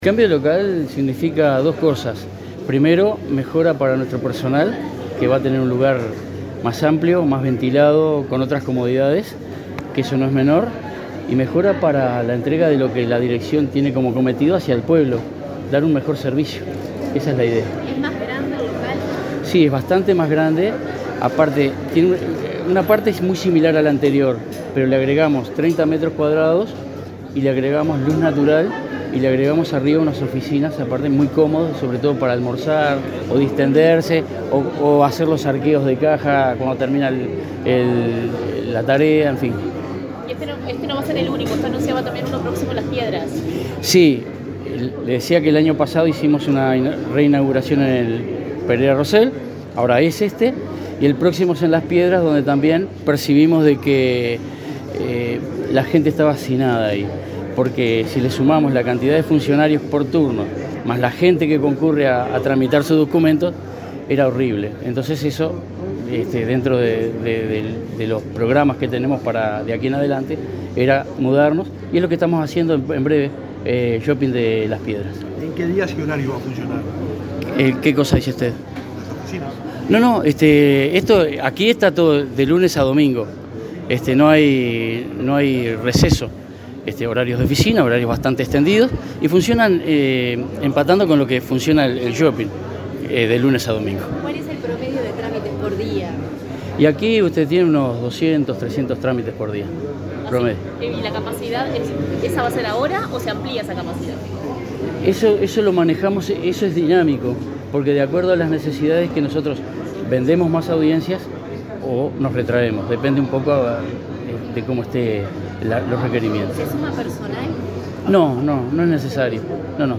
Declaraciones a la prensa de jerarcas del Ministerio del Interior
Declaraciones a la prensa de jerarcas del Ministerio del Interior 14/07/2022 Compartir Facebook X Copiar enlace WhatsApp LinkedIn El director nacional de Identificación Civil, José Luis Rondán, y el ministro del Interior, Luis Alberto Heber, participaron en la reinauguración de la oficina de esa repartición en el centro comercial Parque Roosevelt, en Canelones. Luego, ambos jerarcas dialogaron con la prensa.